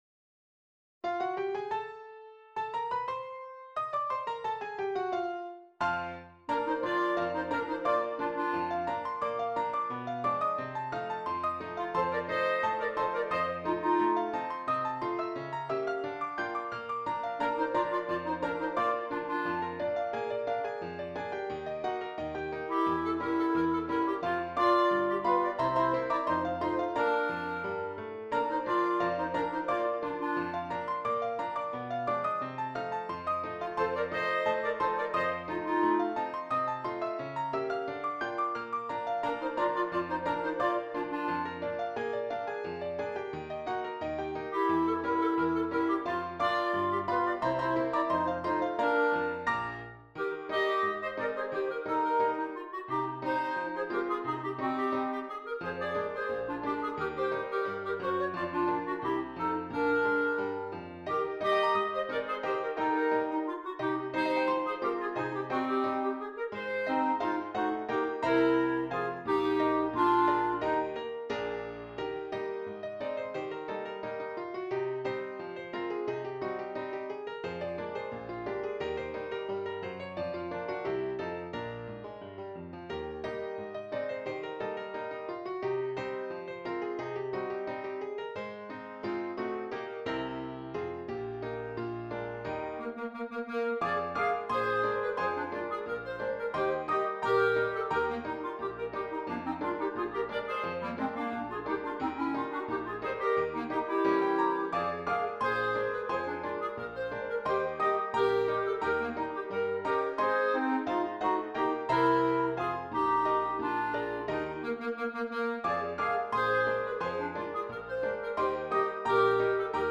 2 Clarinets and Keyboard